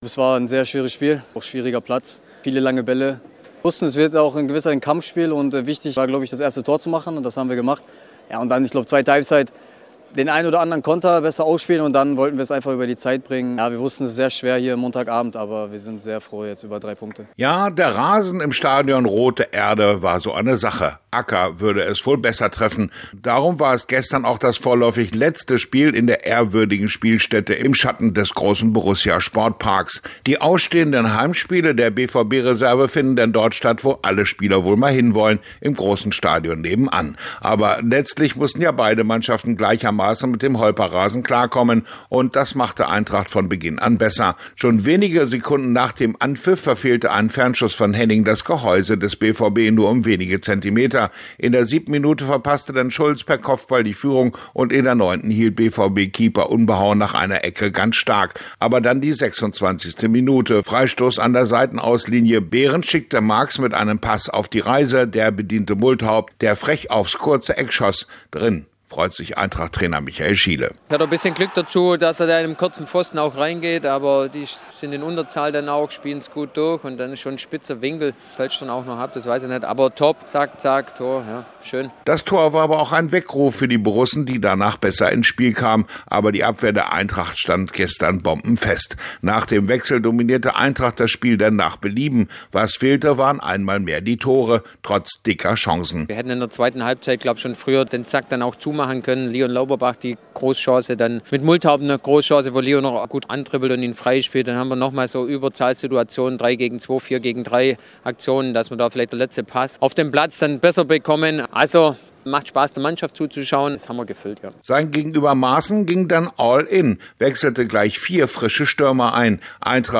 Der war nach dem Abpfiff hörbar erleichtert.